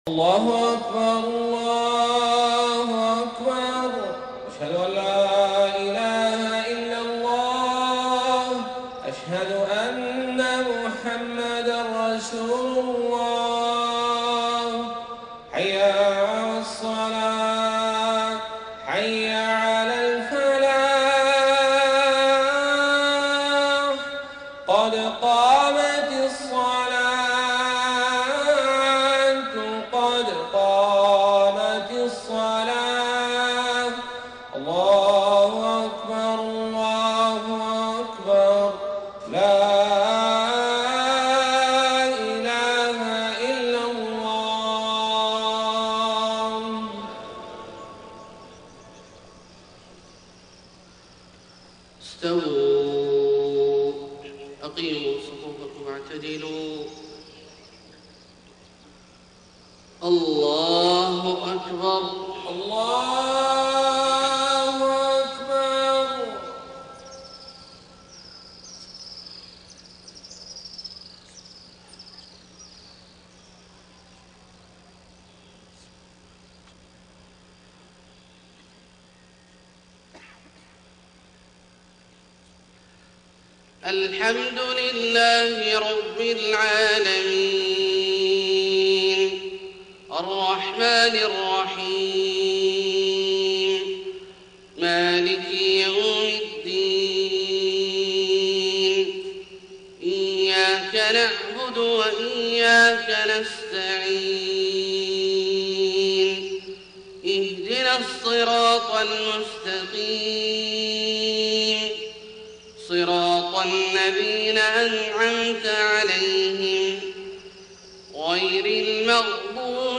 صلاة الفجر 29 صفر 1431هـ من سورة ال عمران 18-32 > 1431 🕋 > الفروض - تلاوات الحرمين